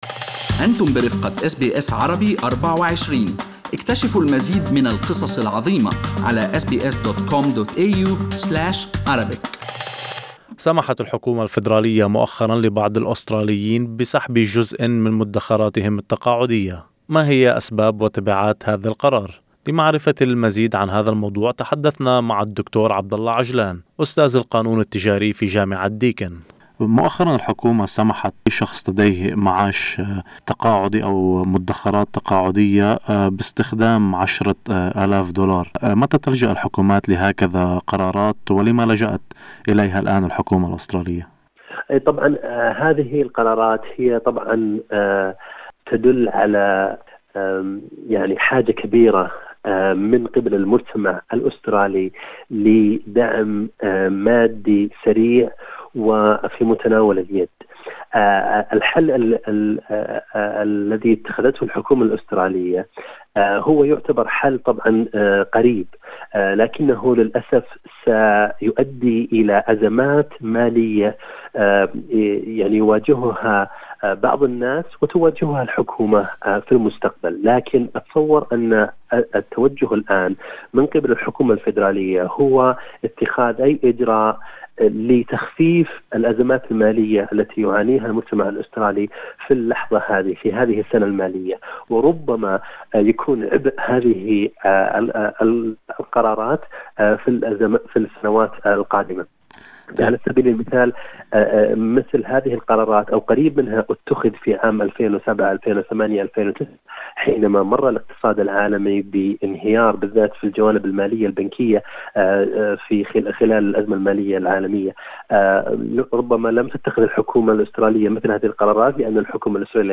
في هذا اللقاء نسلط الضوء على تبعات هذا القرار على مدخراتك التقاعدية